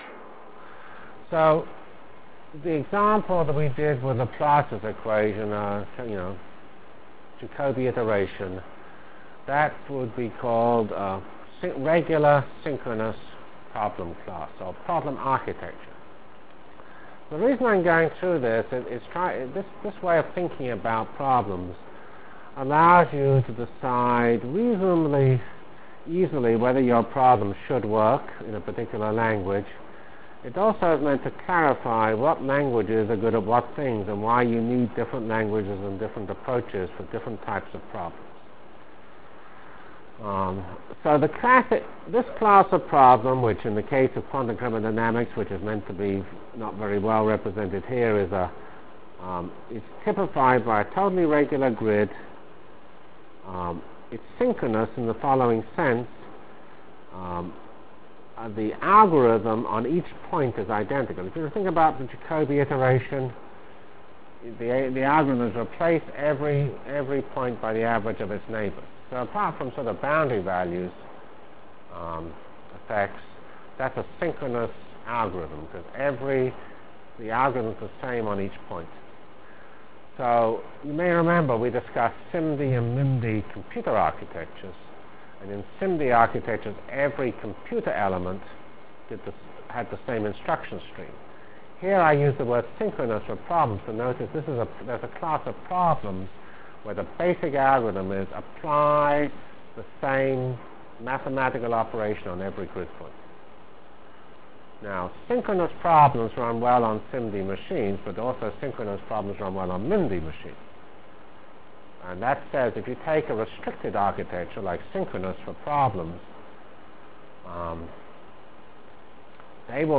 From CPS615-Introduction to F90 Features, Rationale for HPF and Problem Architecture Delivered Lectures of CPS615 Basic Simulation Track for Computational Science -- 24 September 96. by Geoffrey C. Fox *